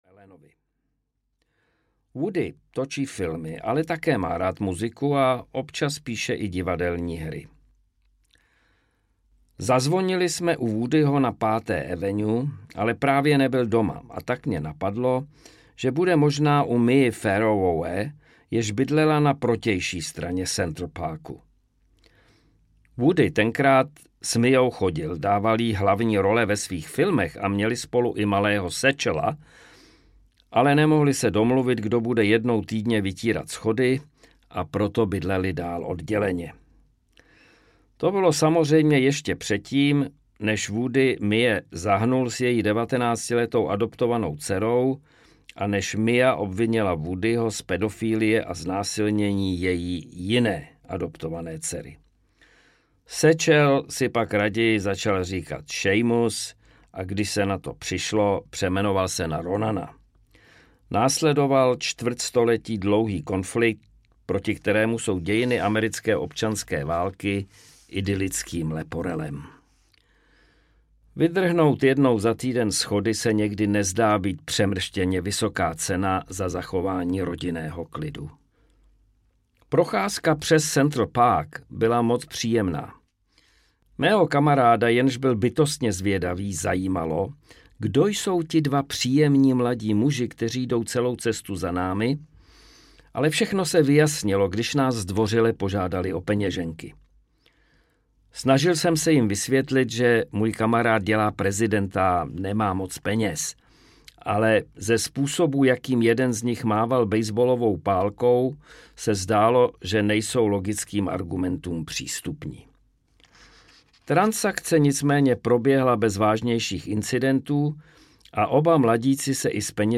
S prominutím řečeno audiokniha
Ukázka z knihy
• InterpretMichael Žantovský